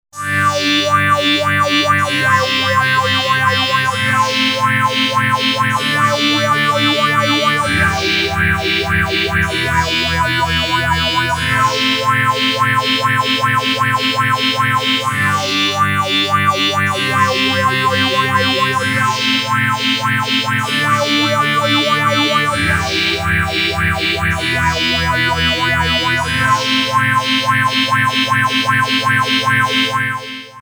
LFO pad
Class: Synthesizer